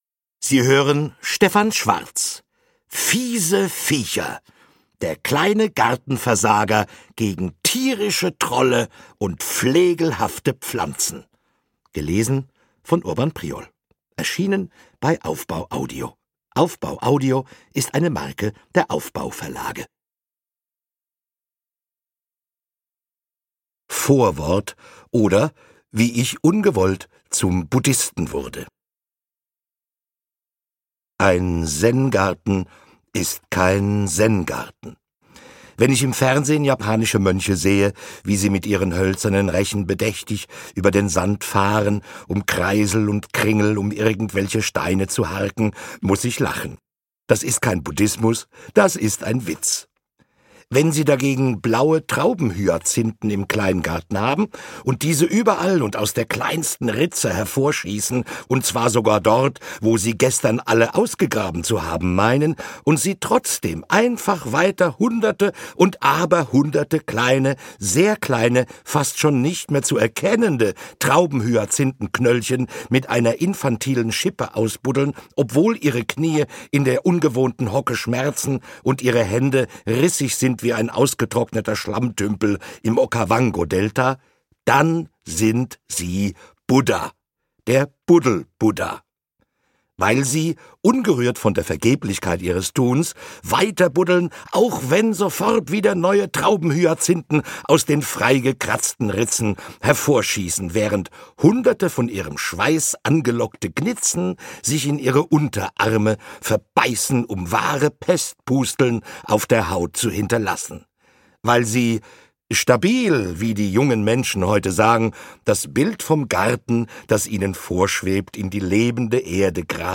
Urban Priol liest
Wunderbar gelesen von Urban Priol!